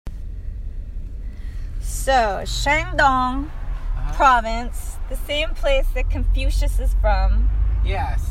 TEMP: Qing Dao province
UEQing-Dao-province.mp3